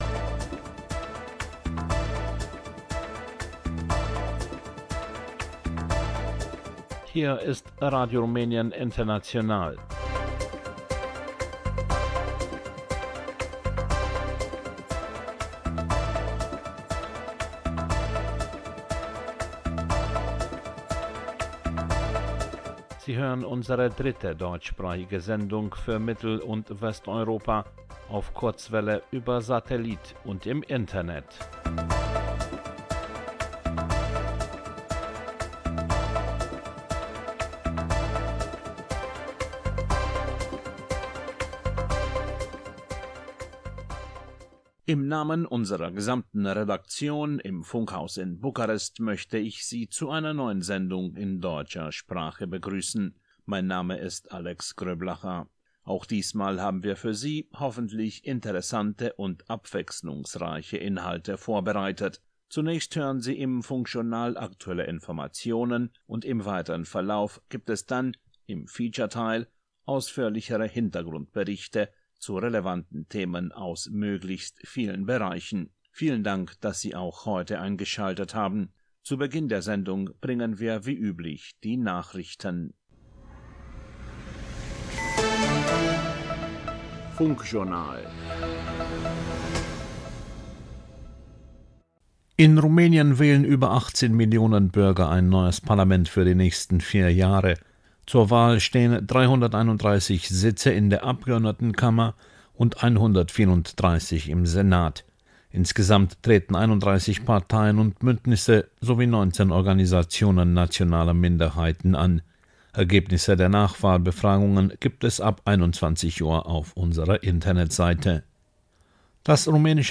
Nachrichten, Radiotour, Funkbriefkasten, Jazz/Blues, Sonntagsstraße